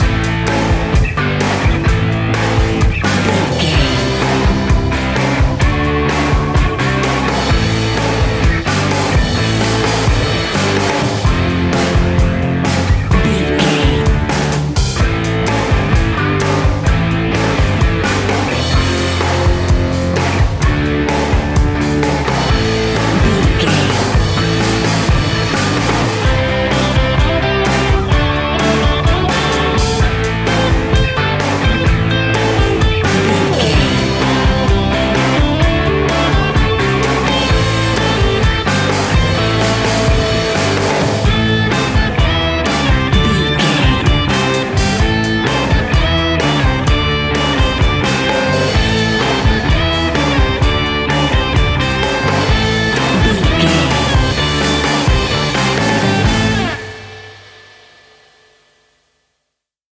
Ionian/Major
A♭
hard rock
distortion
instrumentals